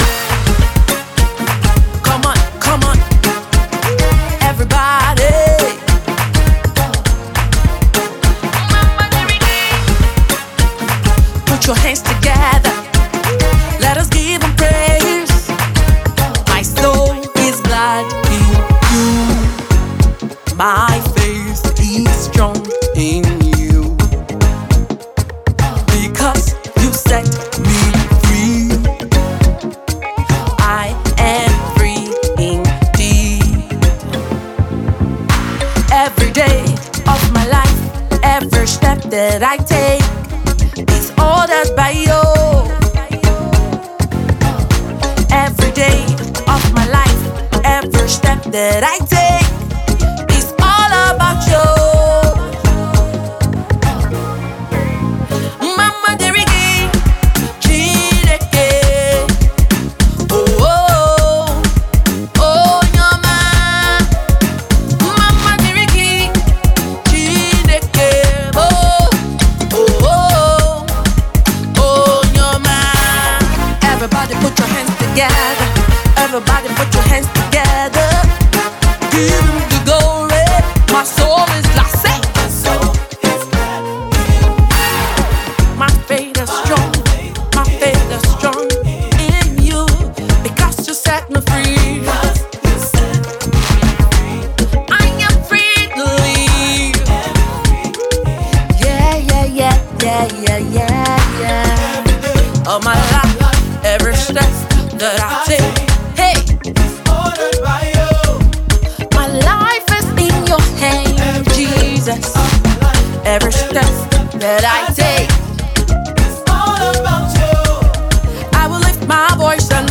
US-based Nigerian songstress